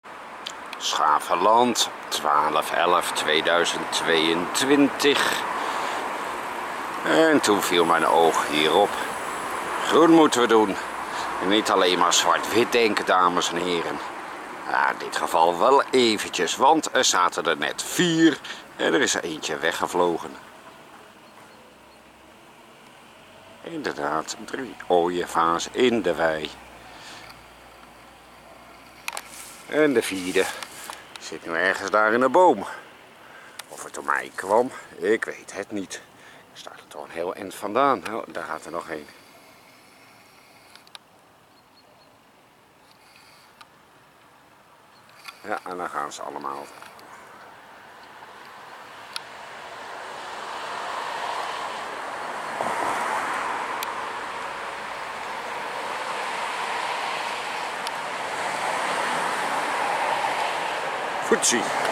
2022-11-11 OOIEVAARS 's GRAVELAND WEIDE + IN DE LUCHT Totaal